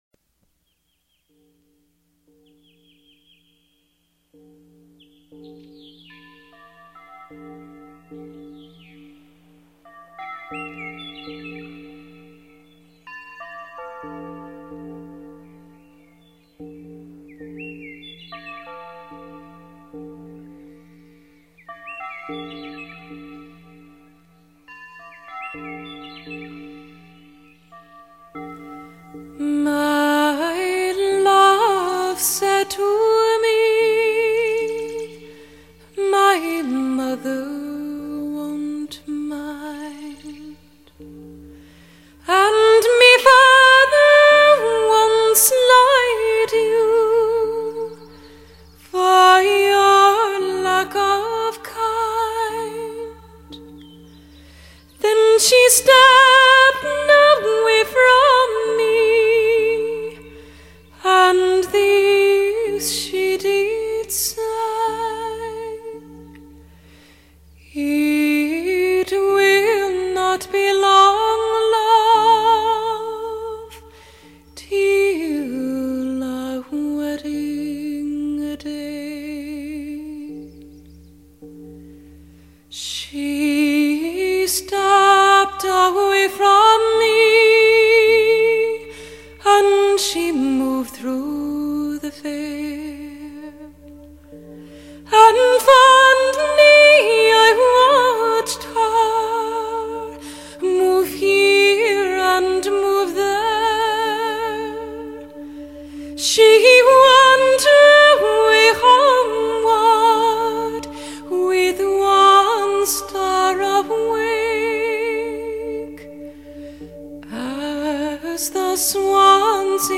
音乐类别：爵士人声
一切的一切，目的就是为了表现凯尔特音乐特有的旋律和味道。
音乐的旋律固然极其古朴而悠扬，但是歌词听来都具有爱尔兰特有的韵味。